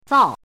汉字“皂”的拼音是：zào。
皂的拼音与读音
zào.mp3